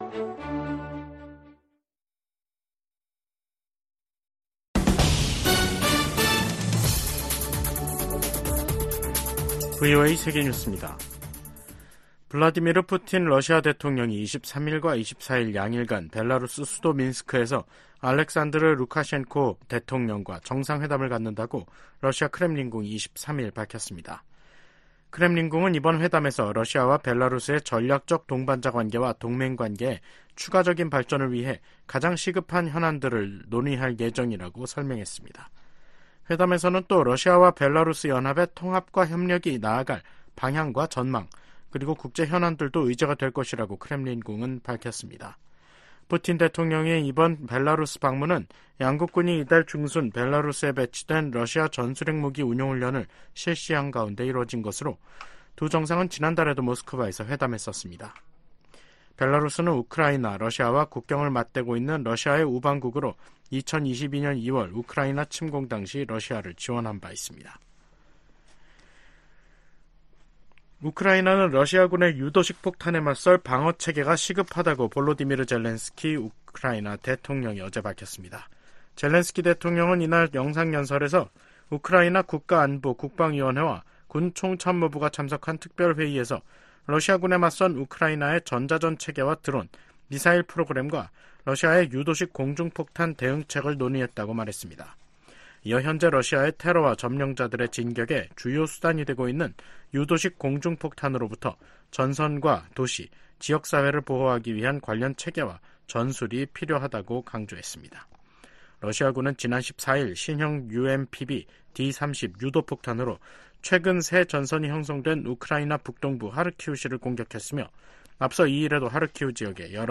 VOA 한국어 간판 뉴스 프로그램 '뉴스 투데이', 2024년 5월 23일 3부 방송입니다. 토니 블링컨 국무장관은, 미국이 한국, 일본과 전례 없는 방식으로 공조하고 있다고 하원 청문회에서 증언했습니다. 북한이 러시아에 군수품을 제공하면서 러시아가 무기 생산을 확대할 기회를 마련해줬으며, 러시아는 그 대가로 북한에 기술을 제공할 수 있다고 미국 합참의장이 평가했습니다. 미국 와이오밍주가 북한과 연계된 회사 3곳에 폐쇄 조치를 내렸습니다.